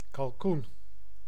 Ääntäminen
IPA: [ɛ̃ dɛ̃.dɔ̃]
IPA: /dɛ̃.dɔ̃/